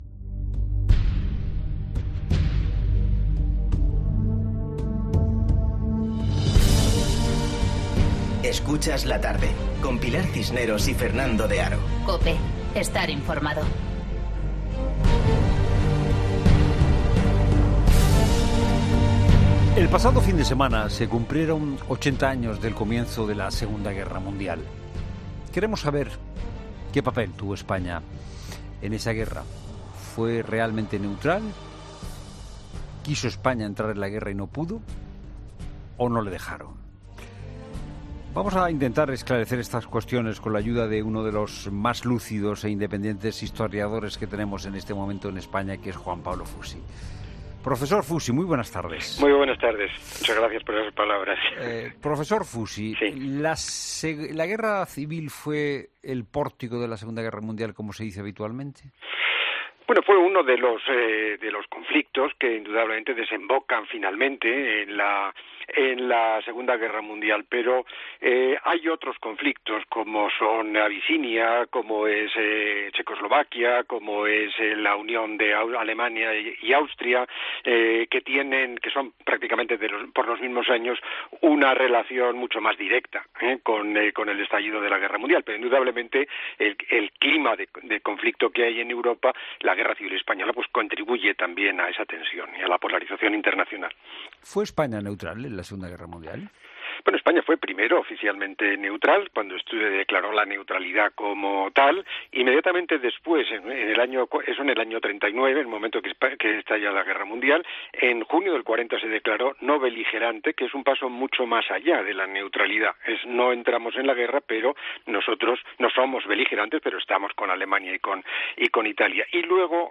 El historiador Juan Pablo Fusi analiza en La Tarde los movimientos diplomáticos españoles durante un conflicto que asoló a toda Europa
En La Tarde de COPE hemos hablado con el catedrático de Historia Contemporánea de la Universidad Complutense de Madrid, Juan Pablo Fusi, que nos ha ayudado a arrojar luz sobre el papel de España en el conflicto.